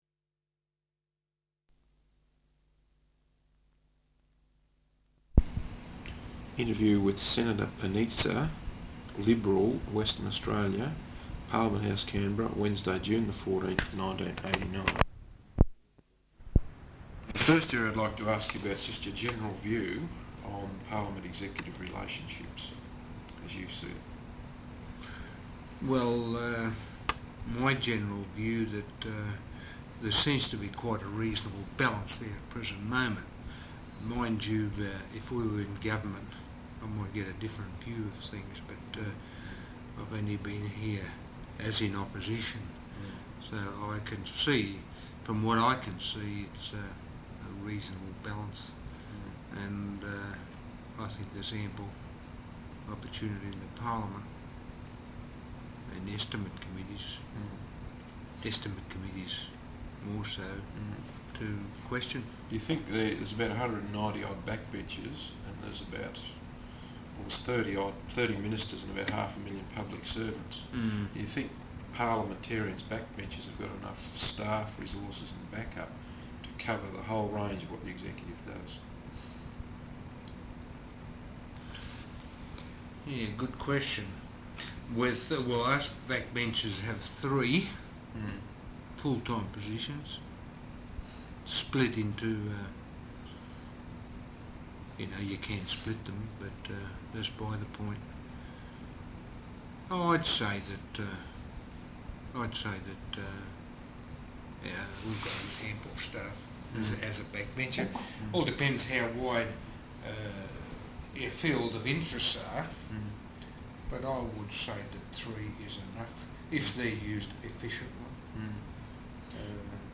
Interview with Senator John Panizza, Liberal Senator for Western Australia, Parliament House, Canberra.